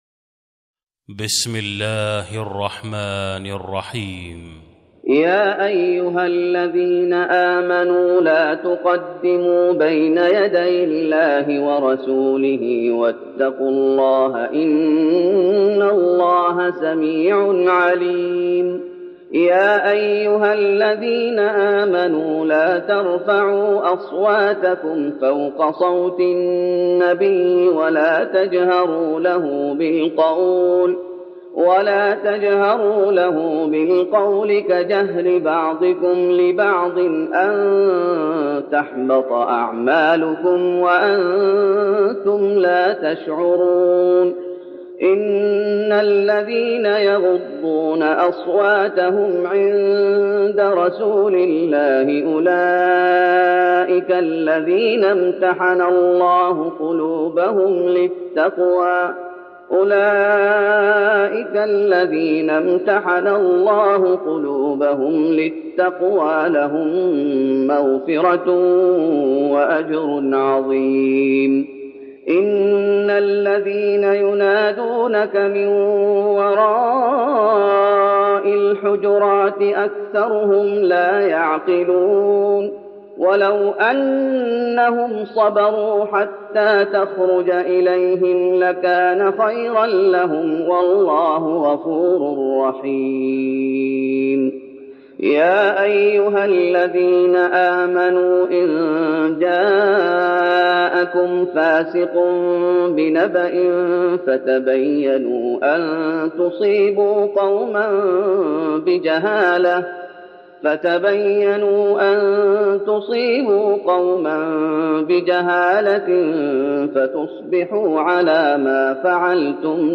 تراويح رمضان 1412هـ من سورة الحجرات Taraweeh Ramadan 1412H from Surah Al-Hujuraat > تراويح الشيخ محمد أيوب بالنبوي 1412 🕌 > التراويح - تلاوات الحرمين